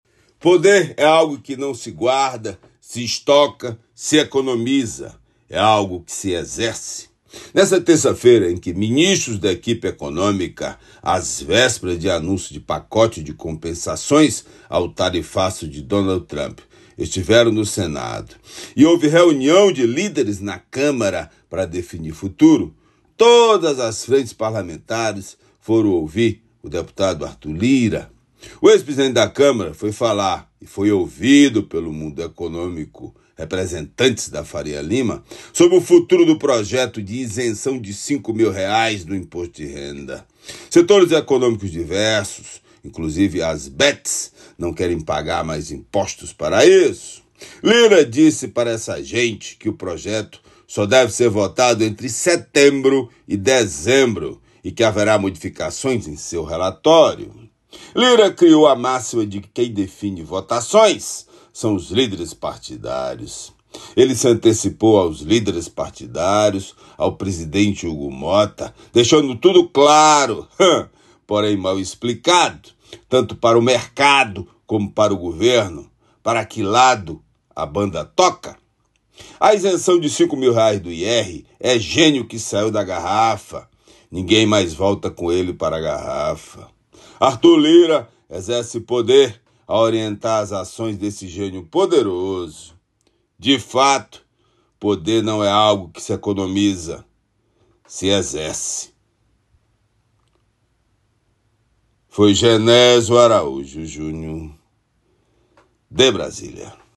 Comentário do jornalista